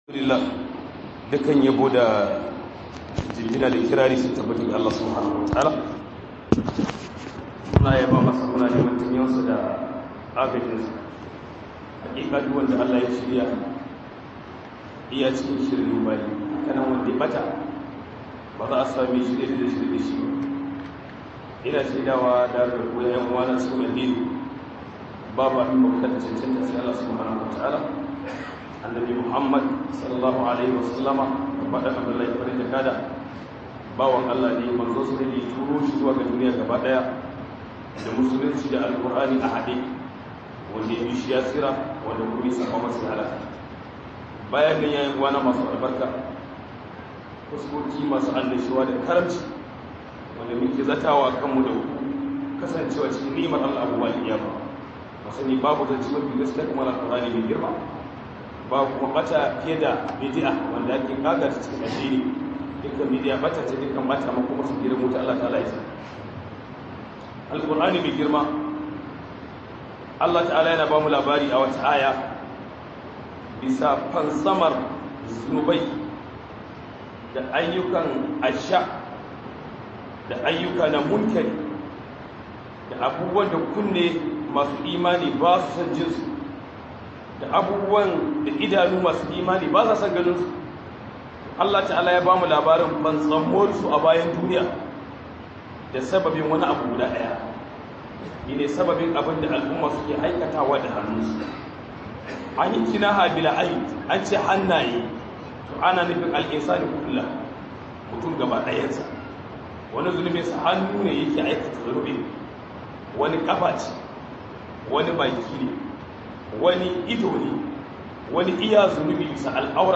074 Huduba Mai Taken Zunubi Da Abunda Yake Haifarwa